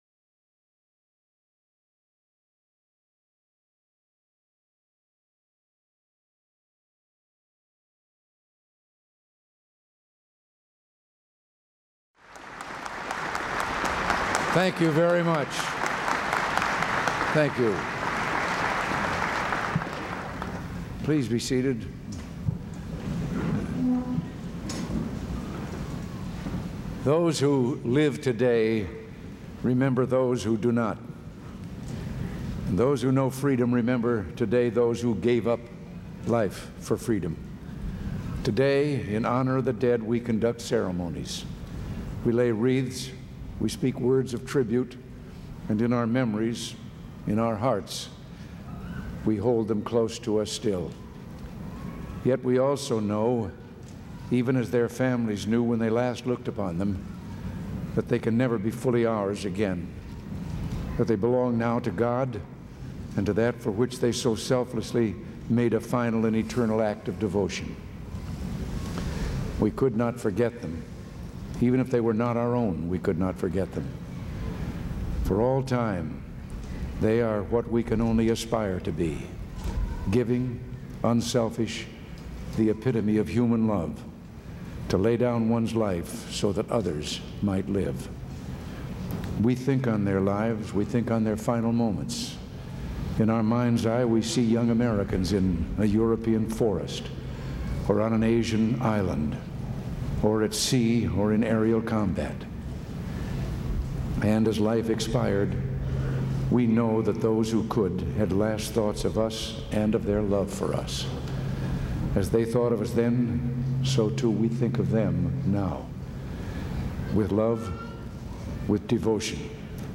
November 11, 1988: Remarks at the Veteran's Day Ceremony